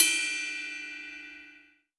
VEC3 Cymbals Ride 21.wav